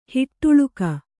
♪ hiṭṭuḷuka